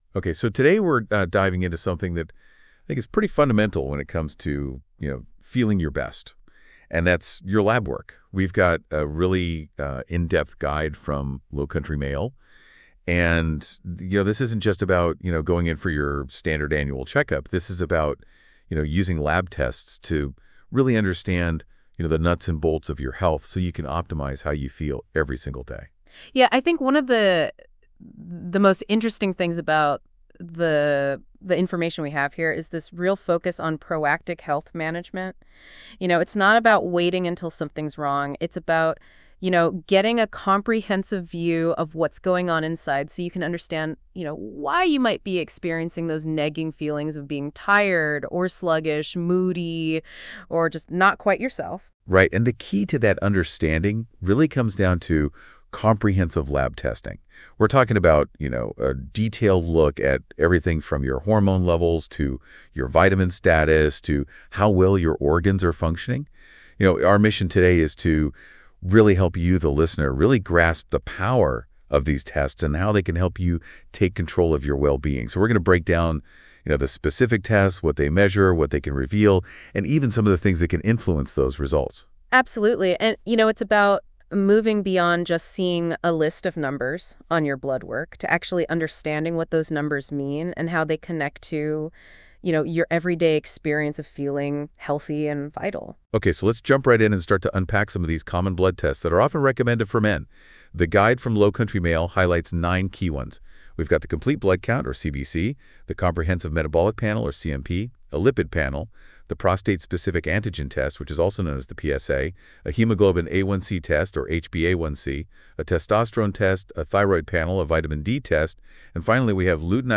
Join us for an insightful podcast interview where we explore the importance of advanced lab work—how it uncovers critical health insights, empowers personalized treatment plans, and helps you take control of your well-being.